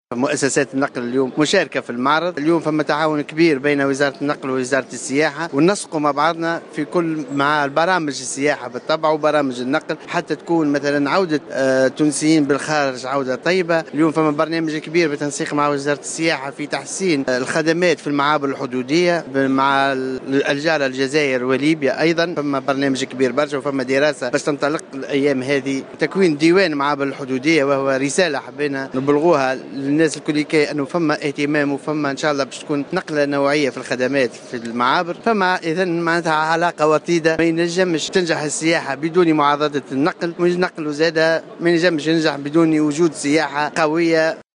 أعلن وزير النقل أنيس غديرة في اختتام الصالون الدولي للسياحة في سوسة اليوم السبت 28 ماي 2016 أن هناك برنامجا ،بالتنسيق مع وزارة السياحة لتحسين الخدمات في المعابر الحدودية خاصة مع الجارتين الجزائر وليبيا.